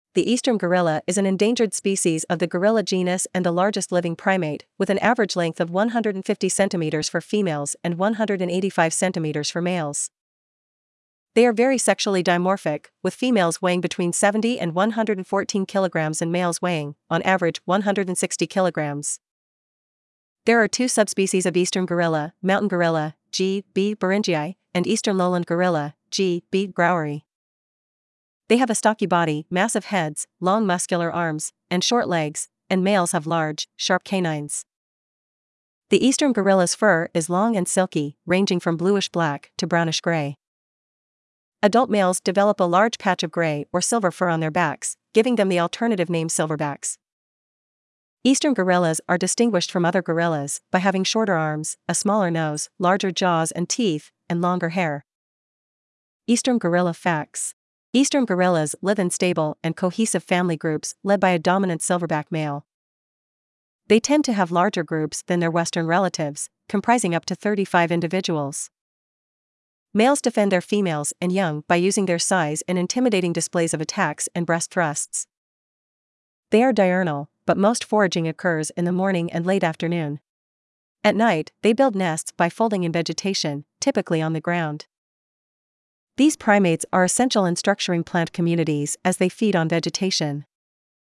Eastern Gorilla
Eastern-Gorilla.mp3